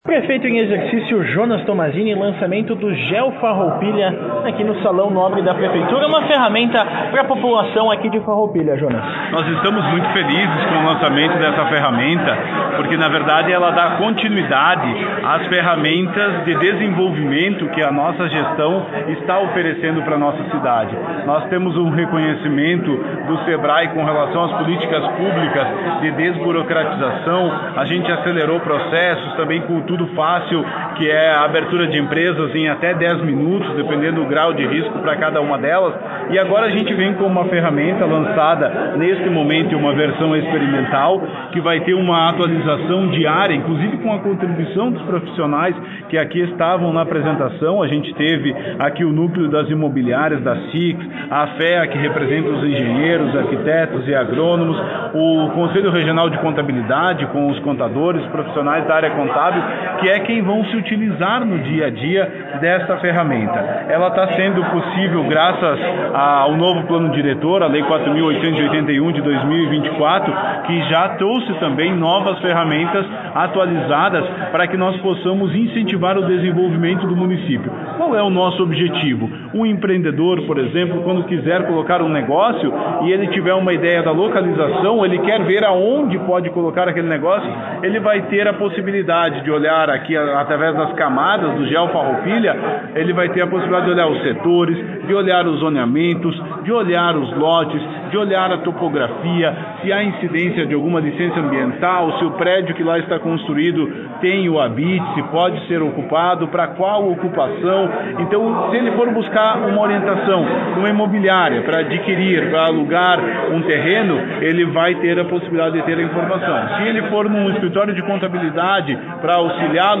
Ouça o prefeito em exercício, Jonas Tomazini